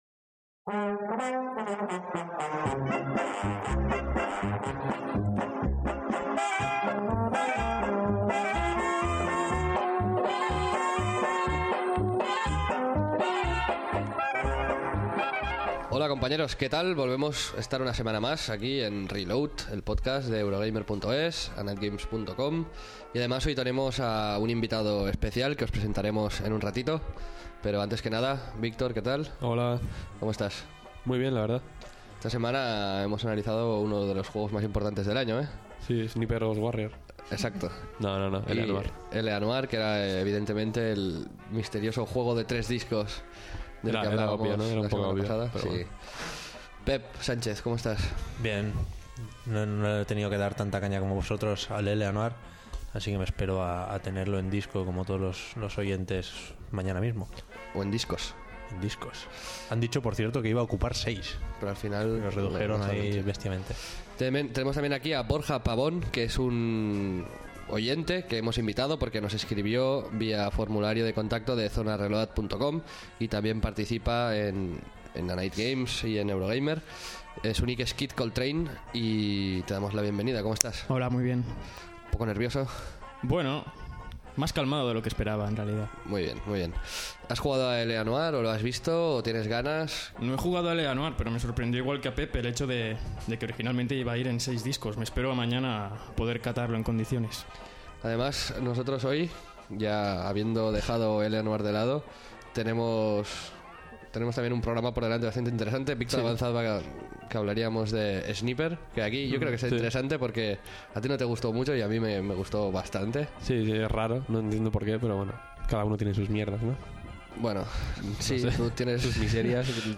También analizamos Sniper: Ghost Warrior y Outland, pero el juego del Team Bondi y Rockstar ocupa casi todo el programa: cerca de una hora de discusión, medio reseña, medio debate, con opiniones para todos los gustos.